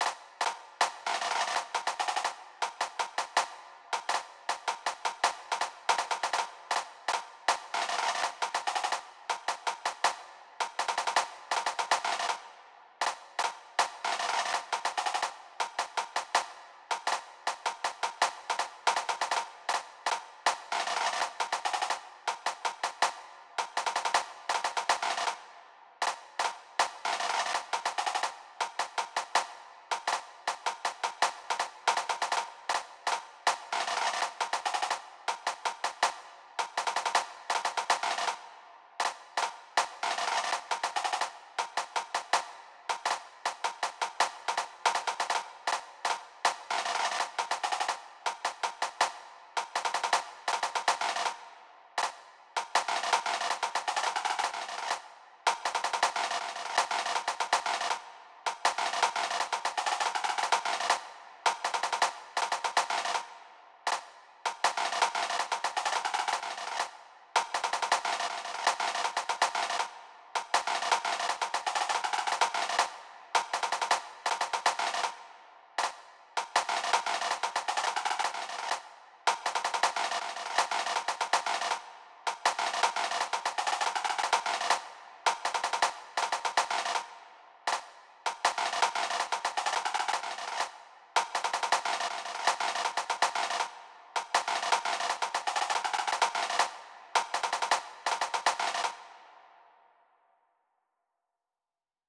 Audio (entire medley on bagpipes)